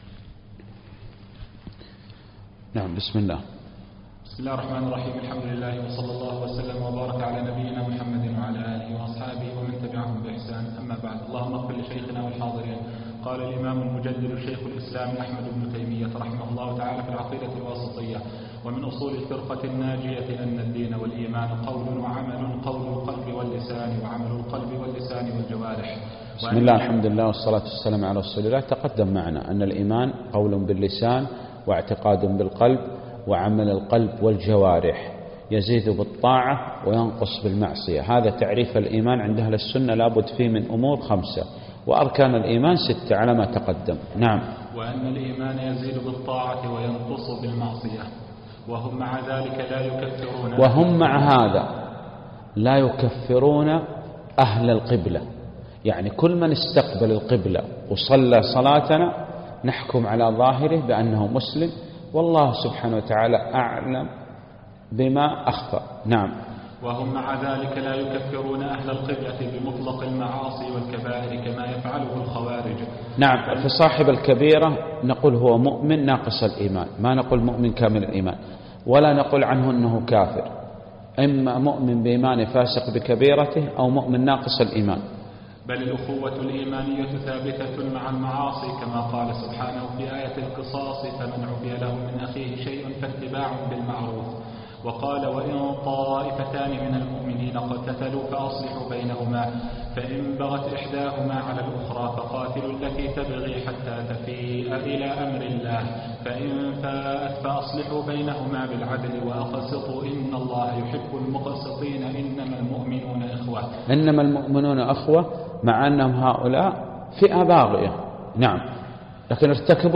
الدرس الرابع